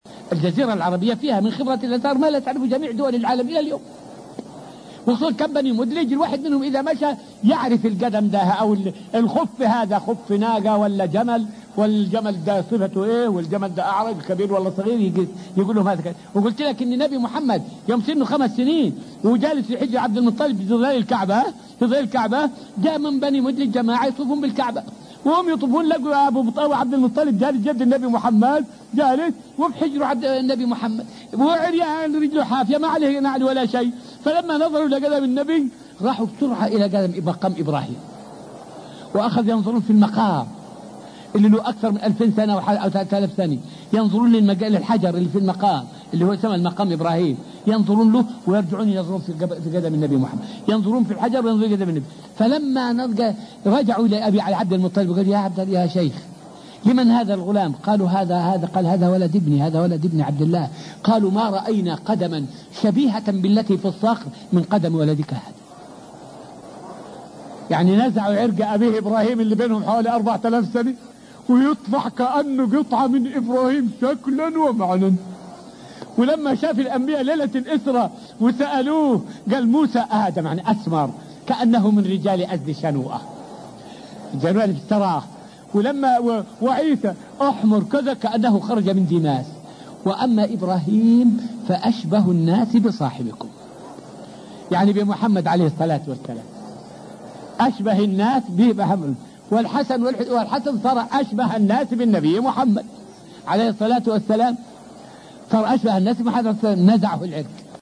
فائدة من الدرس الرابع والعشرون من دروس تفسير سورة البقرة والتي ألقيت في المسجد النبوي الشريف حول خبرة أهل الجزيرة في تقفي الآثار.